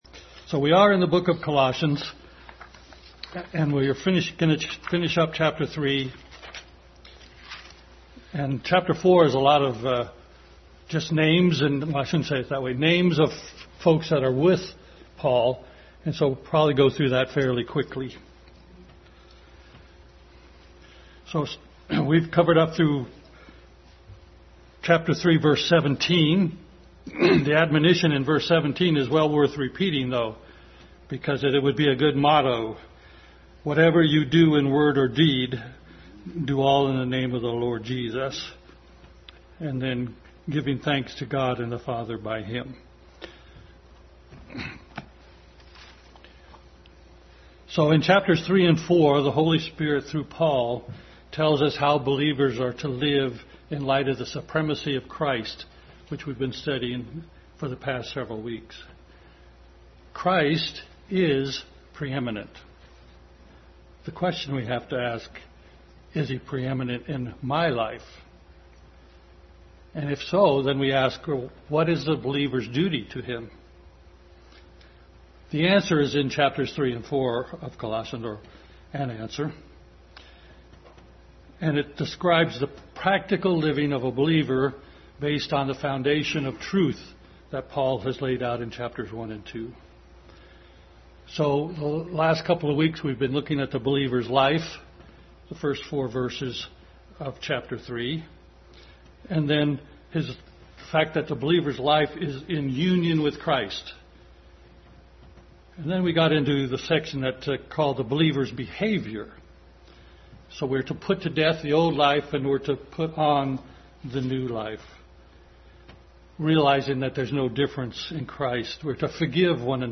Adult Sunday School continued study in Colossians.